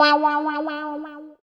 110 GTR 1 -L.wav